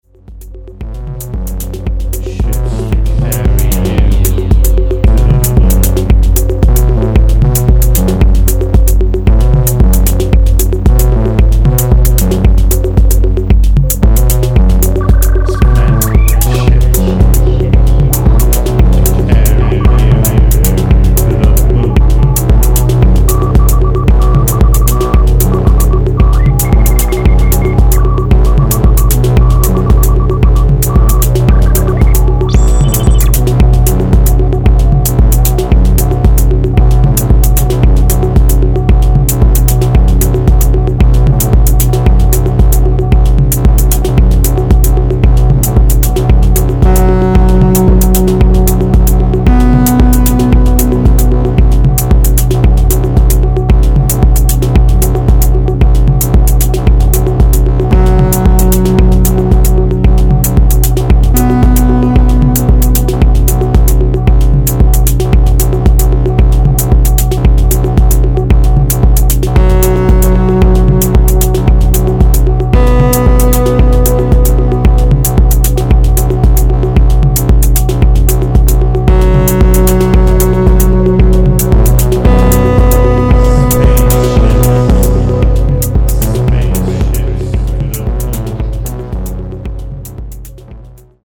House Chicago